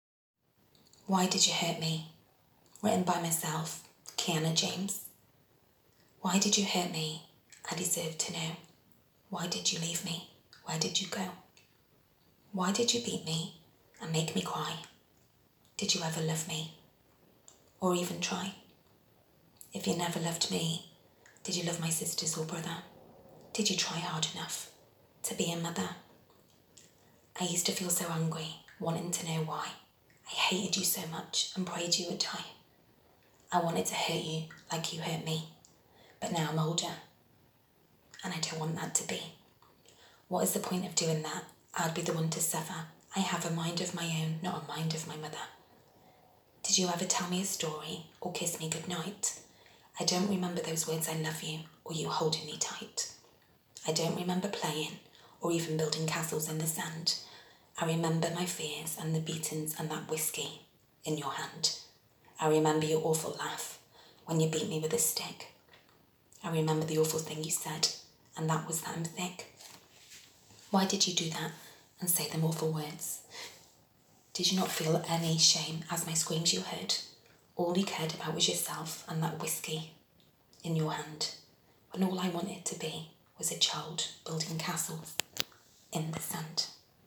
It is a poem